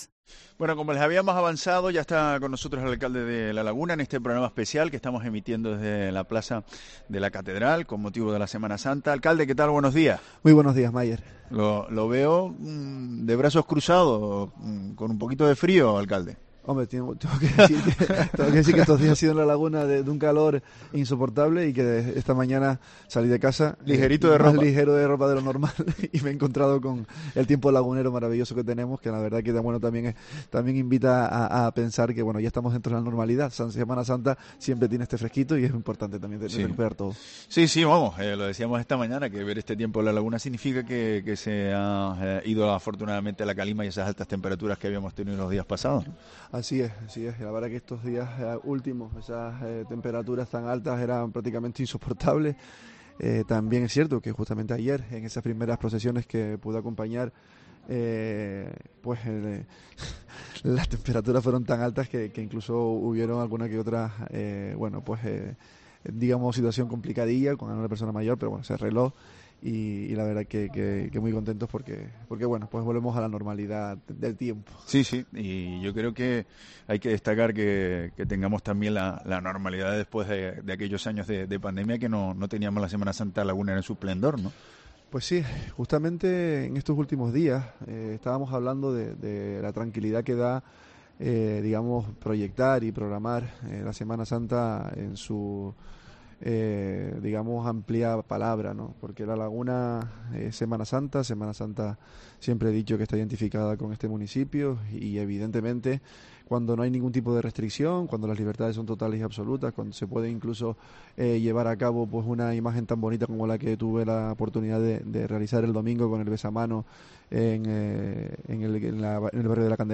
Entrevista Luis Yeray Gutiérrez, alcalde de San Cristóbal de La Laguna